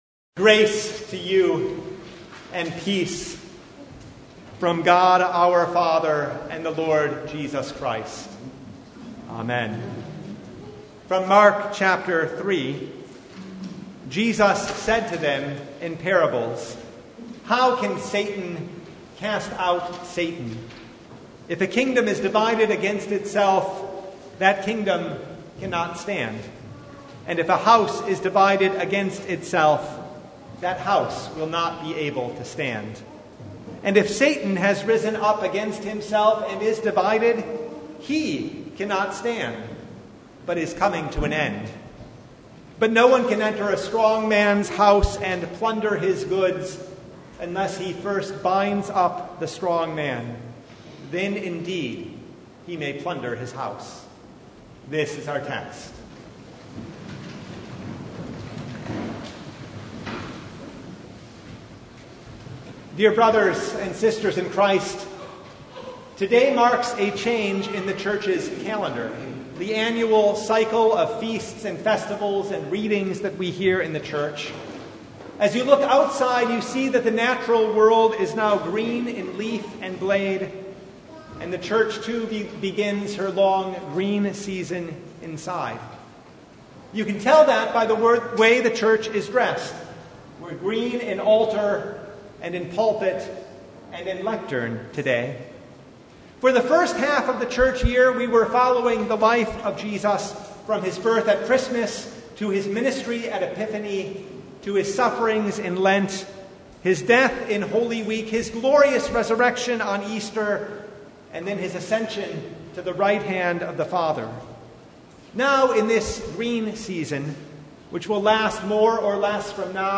Service Type: Sunday
Sermon Only « The First Sunday after Holy Trinity